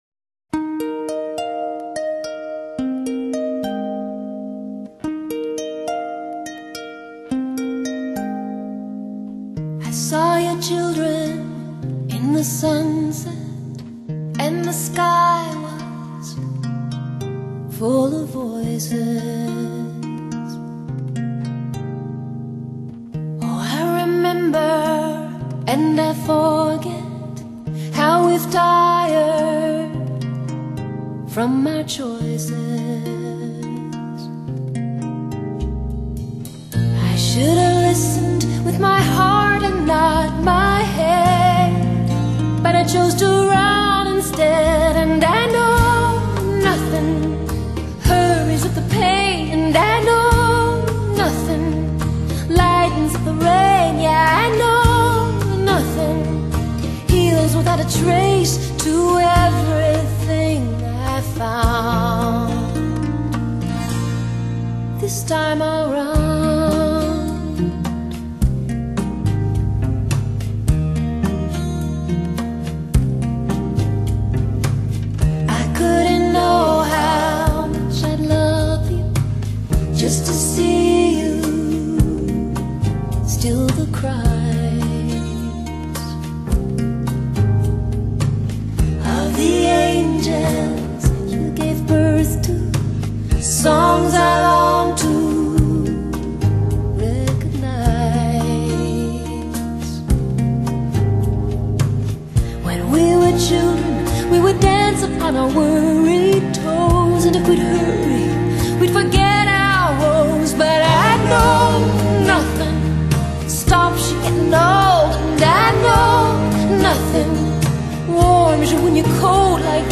她的嗓音清晰純淨、情感豐富，正如她演奏的豎琴音色一樣，充滿了光輝潤澤的質感。